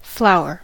flower: Wikimedia Commons US English Pronunciations
En-us-flower.WAV